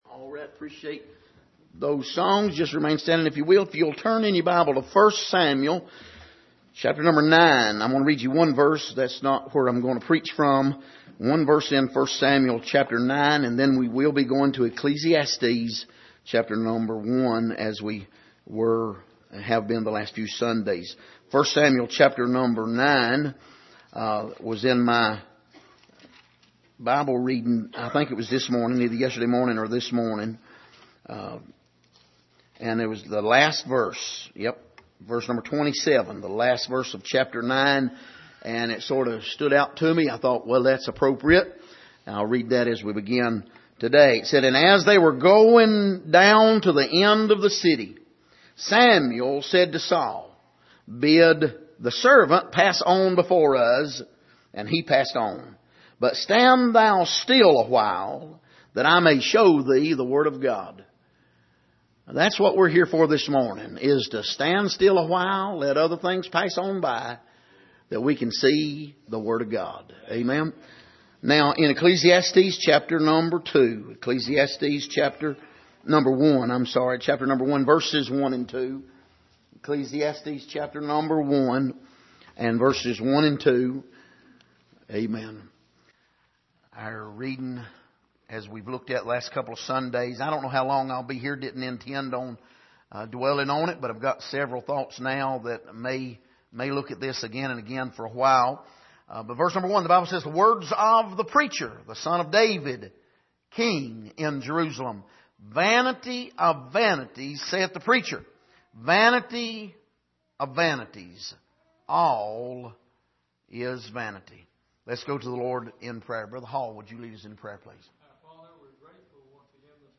Passage: Ecclesiastes 1:1-2 Service: Sunday Morning